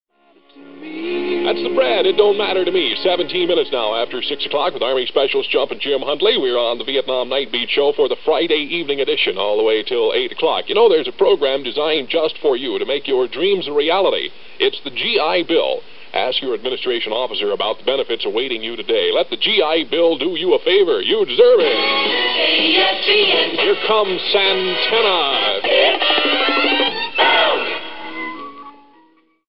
GI Bill PSA